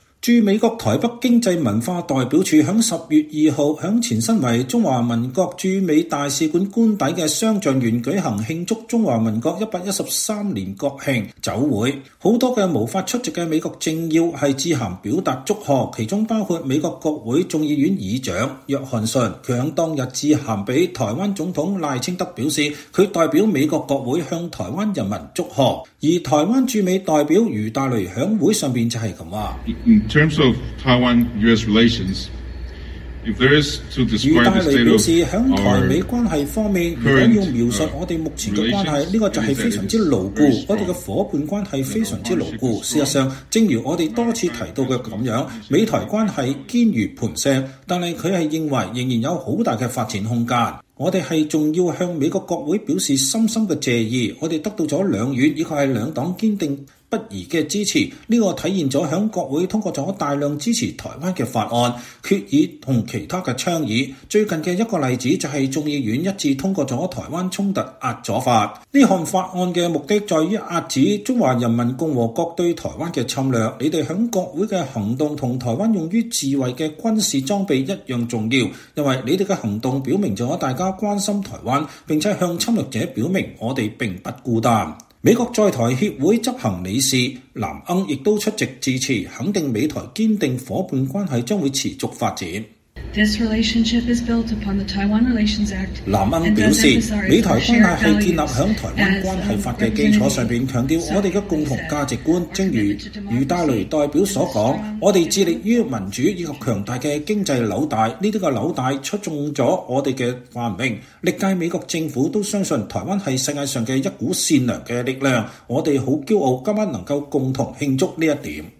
駐美國台北經濟文化代表處10月2日在前身為中華民國駐美大使官邸的雙橡園舉行「慶祝中華民國113年國慶」酒會。
美國在台協會執行董事藍鶯出席致詞，肯定美台堅定夥伴關係將持續發展。